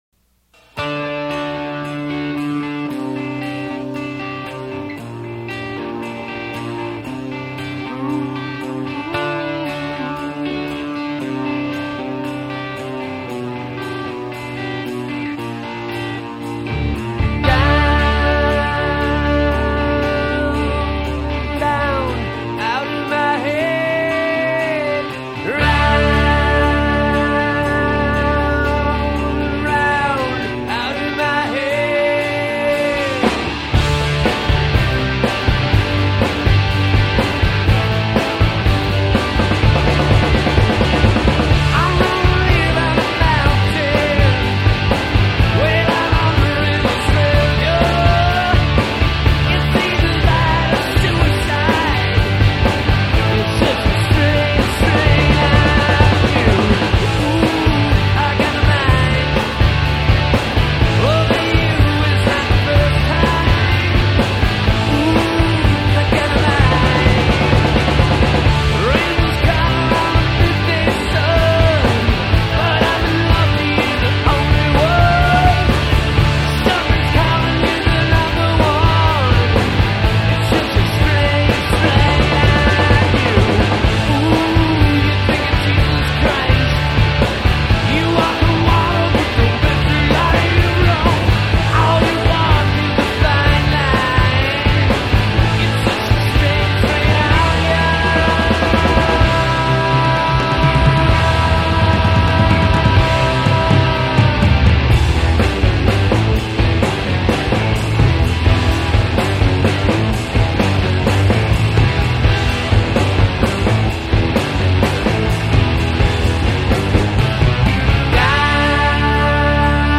The songs really come through with no studio trickery.